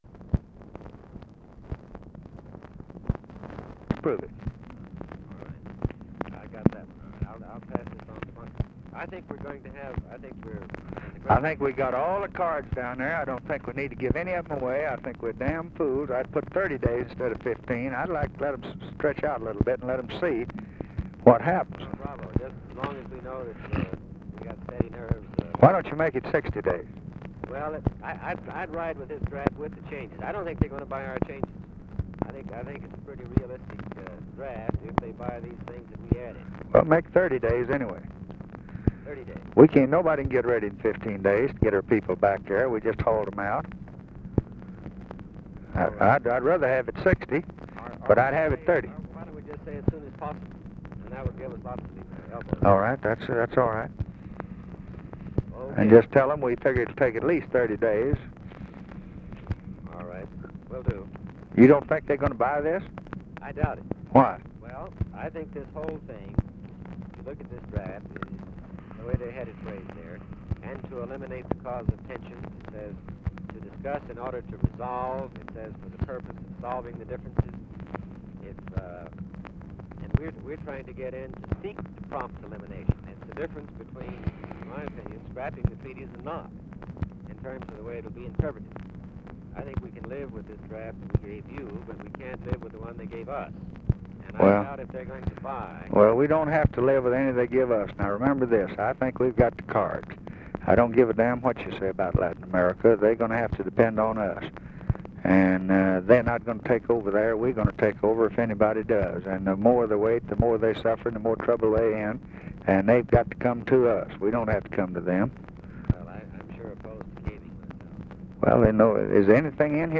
Conversation with THOMAS MANN, January 25, 1964
Secret White House Tapes